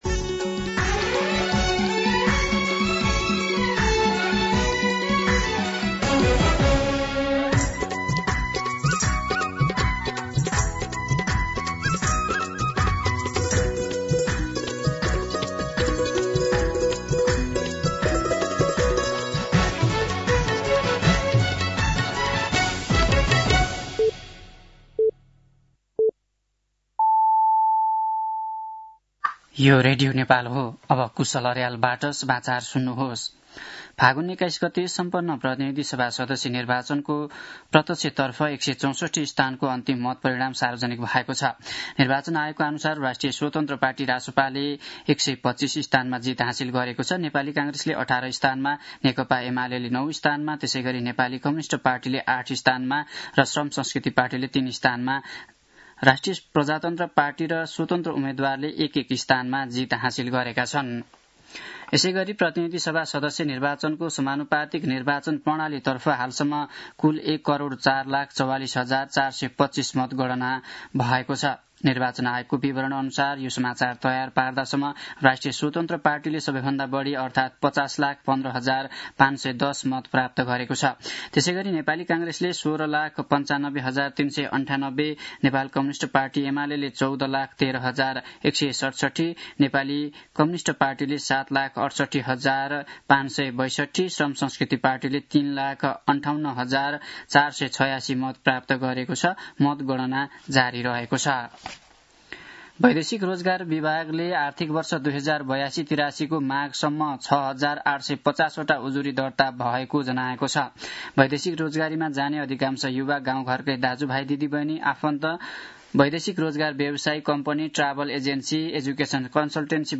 मध्यान्ह १२ बजेको नेपाली समाचार : २६ फागुन , २०८२